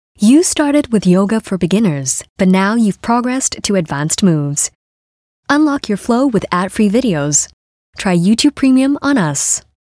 Voice Sample: Yoga
EN Asian EN SG
We use Neumann microphones, Apogee preamps and ProTools HD digital audio workstations for a warm, clean signal path.